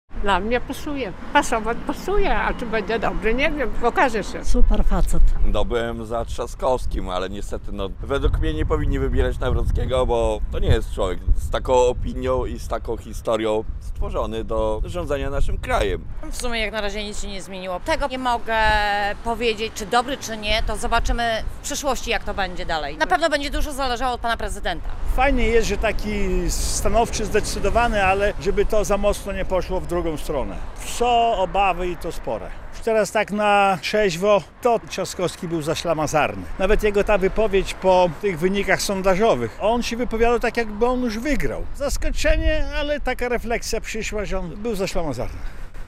Białostoczanie komentują wygraną Karola Nawrockiego